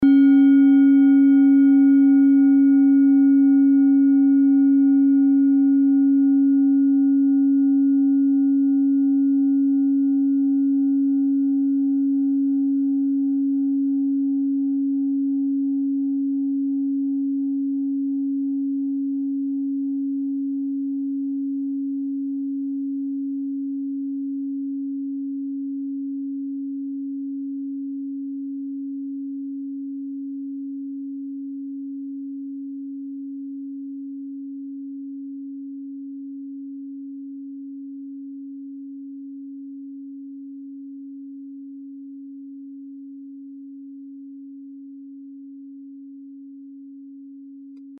Klangschale TIBET Nr.3
Klangschale-Durchmesser: 16,5cm
Sie ist neu und ist gezielt nach altem 7-Metalle-Rezept in Handarbeit gezogen und gehämmert worden.
(Ermittelt mit dem Filzklöppel oder Gummikernschlegel)
klangschale-tibet-3.mp3